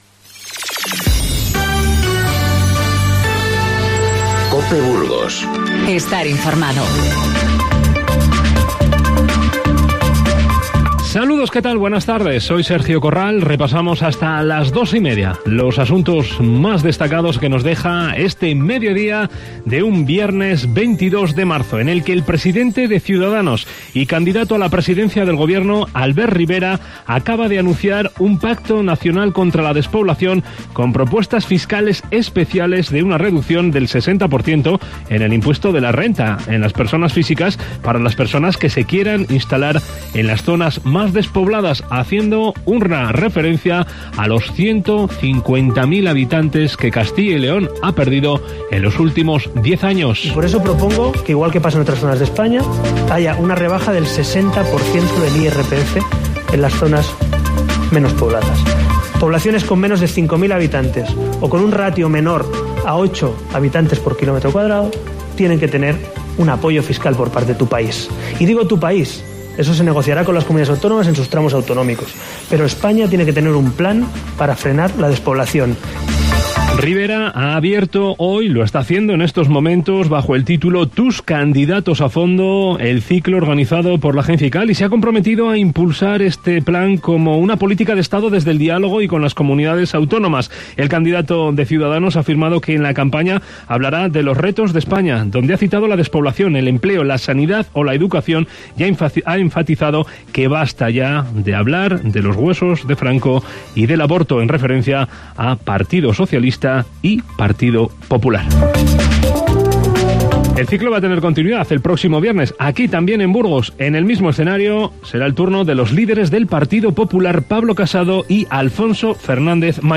Informativo Mediodía COPE Burgos 22/03/19